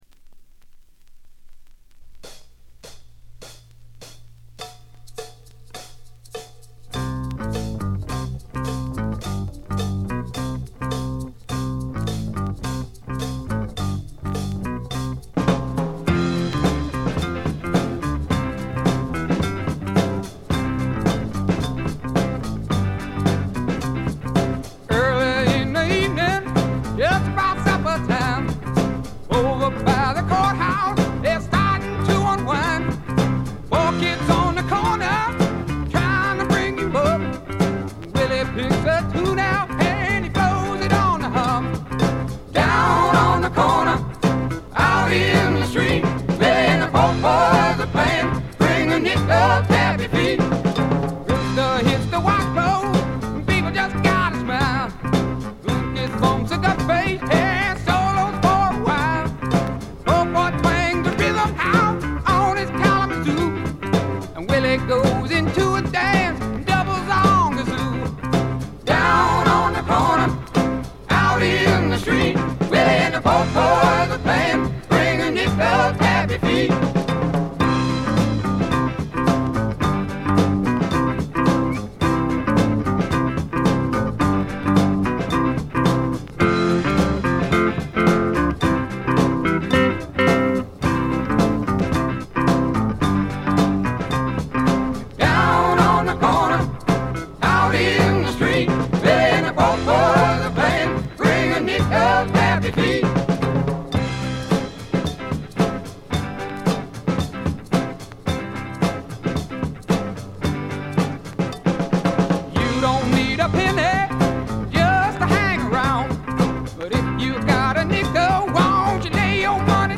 部分試聴ですが、微細なチリプチ程度でほとんどノイズ感無し。
試聴曲は現品からの取り込み音源です。